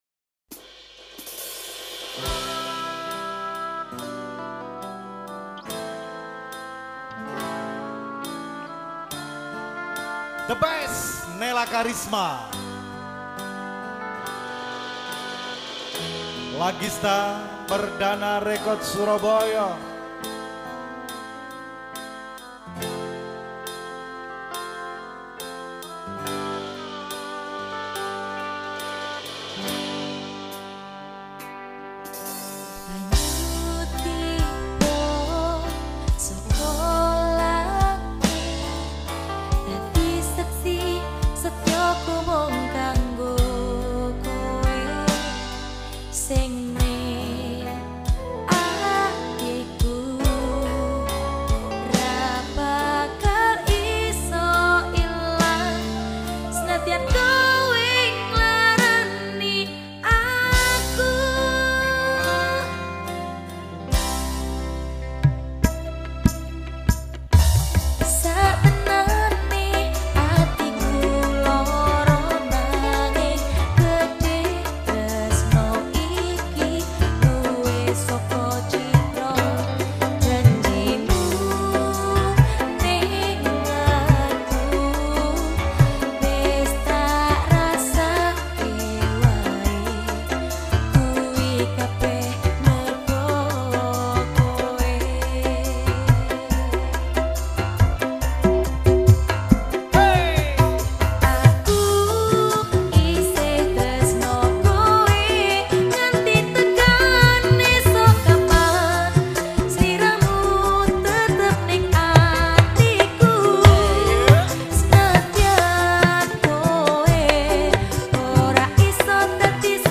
Vokal